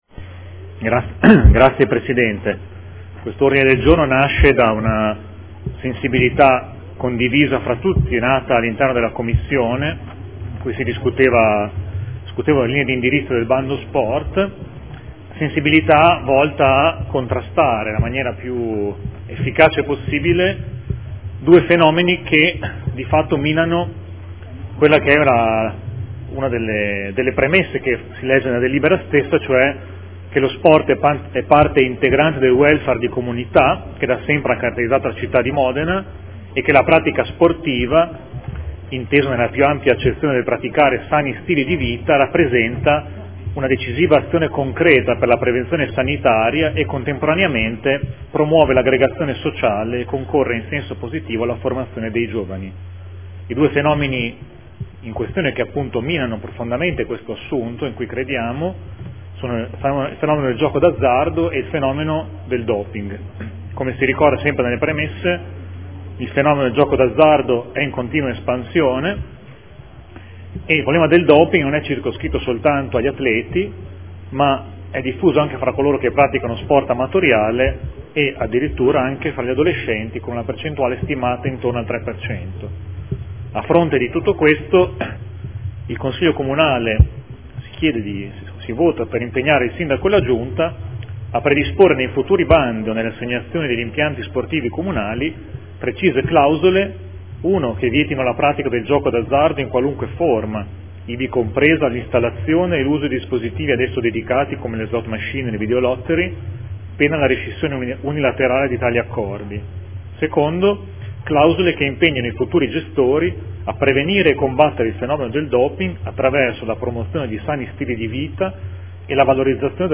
Seduta del 09/04/2015 Presenta Odg n. 64075